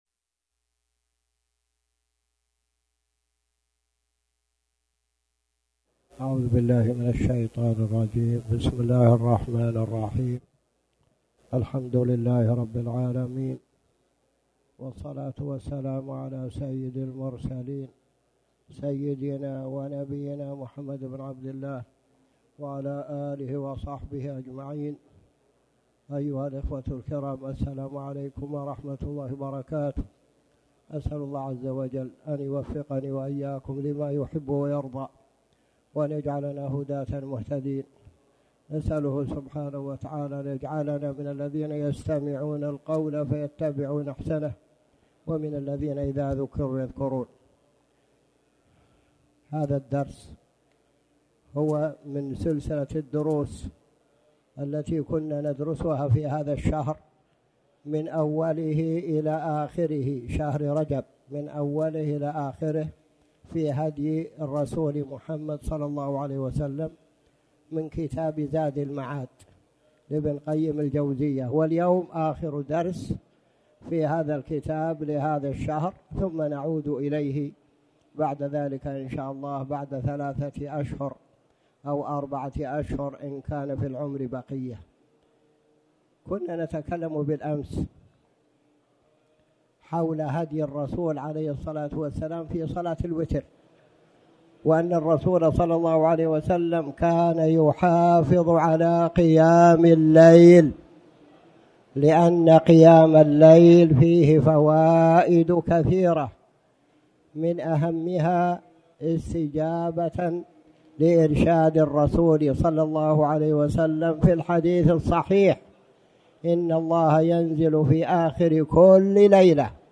تاريخ النشر ٢ شعبان ١٤٣٩ هـ المكان: المسجد الحرام الشيخ